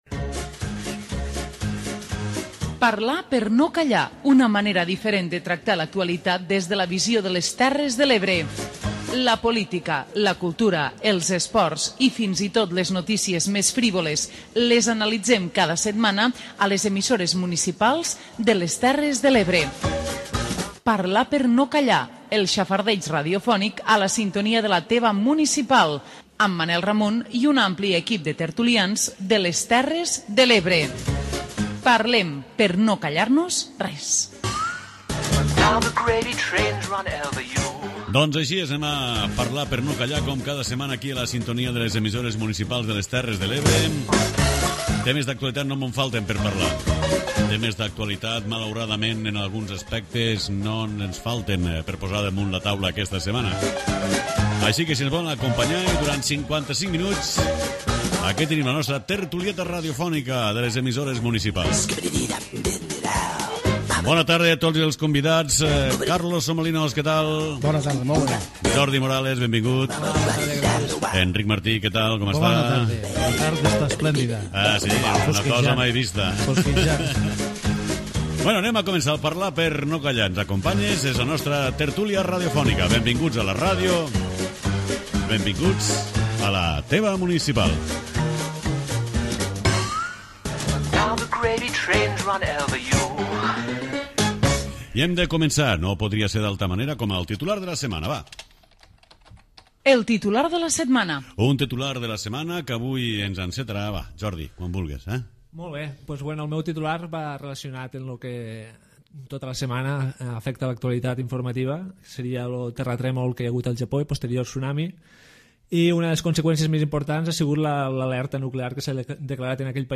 Parlar per no Callar, la tertúlia d'àmbit territorial d'EMUTE. 55 minuts de xerrada distesa amb gent que vol opinar. Busquem la foto, el titular i les carabasses de la setmana.